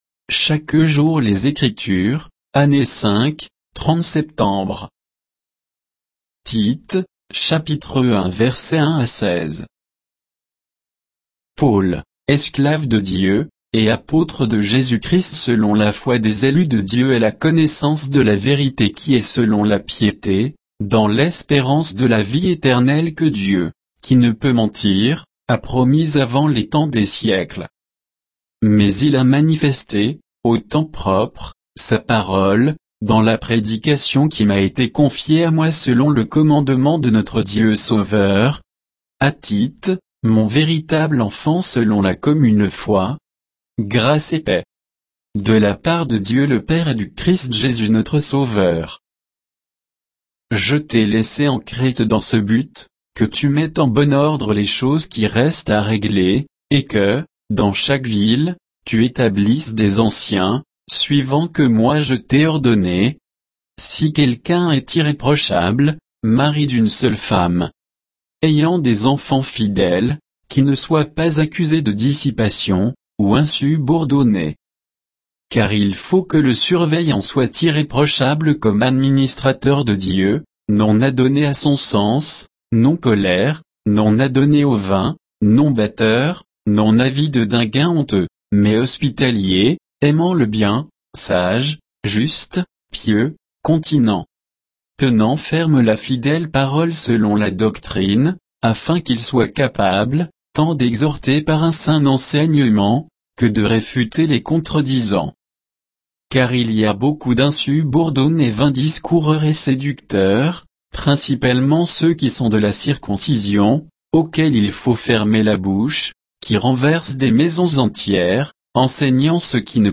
Méditation quoditienne de Chaque jour les Écritures sur Tite 1